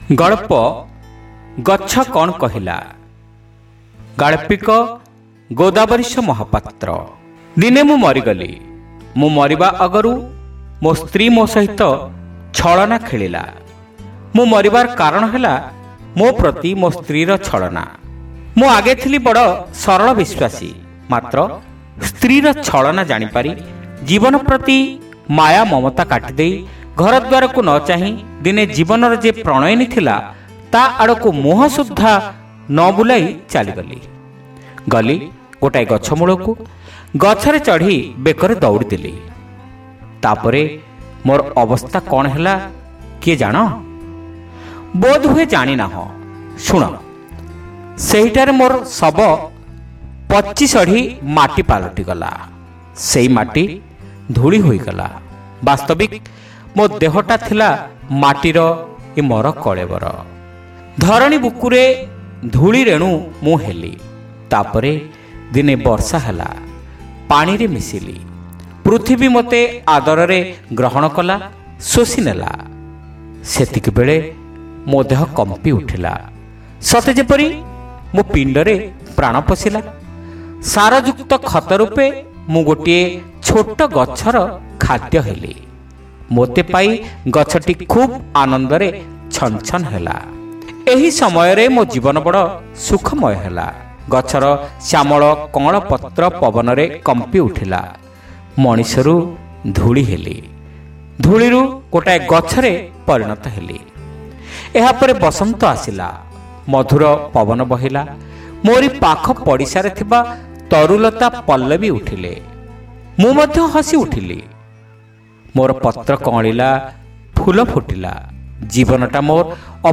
Audio Story : Gachha Kan Kahila